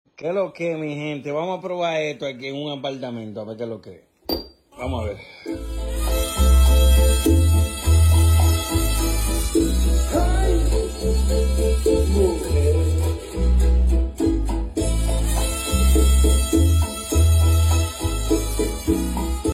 Neverita Modificada Con Bocinas Woofer Subwoofer